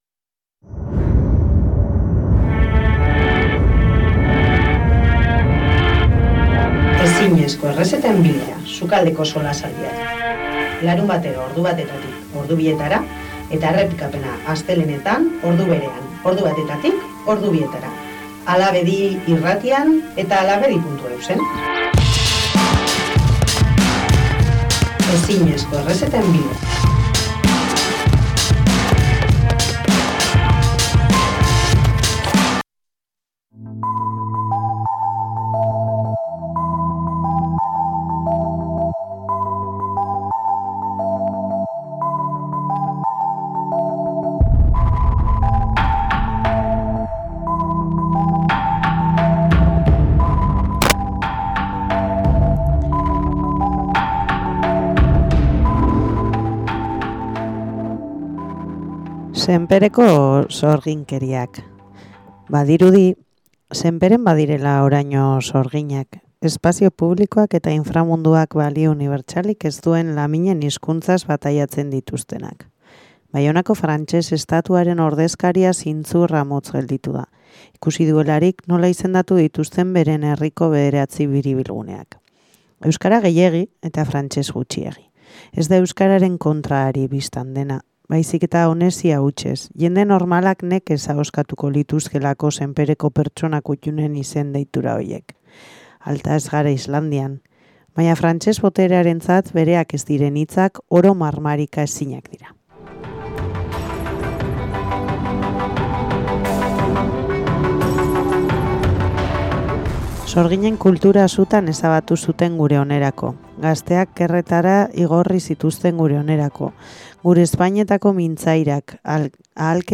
Irratsaio honetan, gure sukaldean, Muinoko mudantzetako kide bati egindako elkarrizketa.